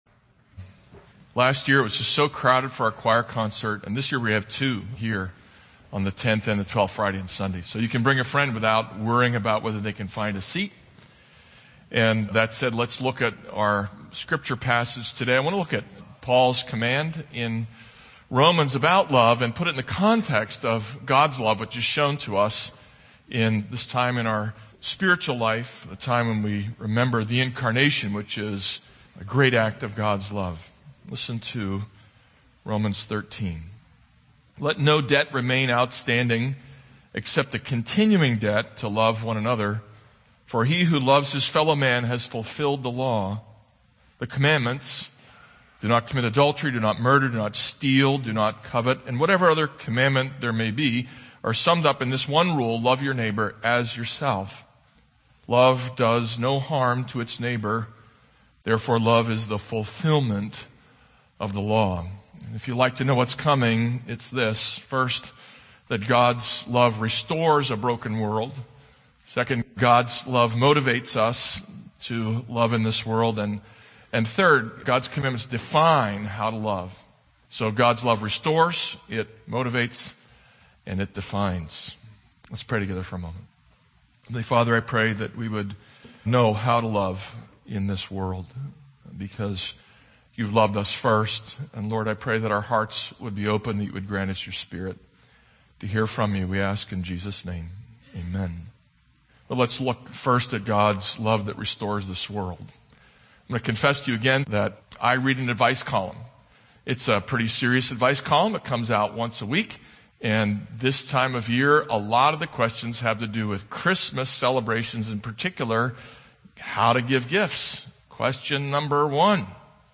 This is a sermon on Romans 13:8-10.